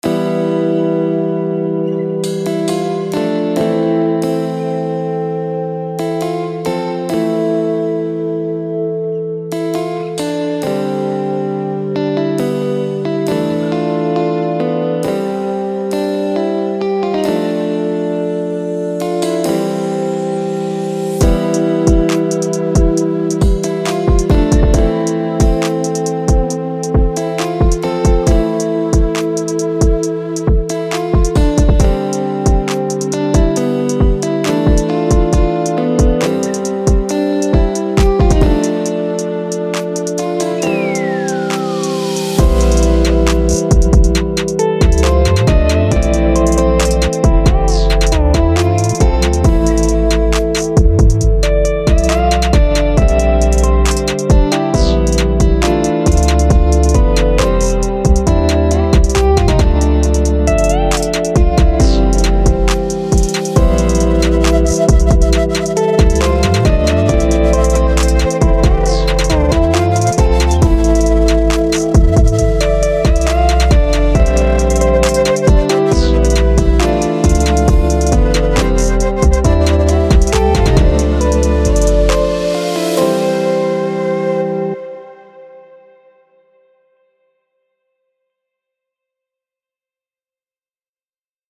השילוב בין מונו לסטריאו עושה את ההבדל הגדול עד כמה מורגש המיקס הלפט ורייט ואם לא טיפה חזק הביט עצמו תקשיבו באוזניות שיעשה לכם את ההבדל 531 - עותק - ע...
ברור שזה טראפ הכל בטראק הזה טראפ אולי הקצב טיפונת זולג לדרייל